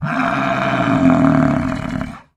Звуки буйвола
Буйвол Альтернативный вариант 2